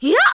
Laudia-Vox_Attack5_kr.wav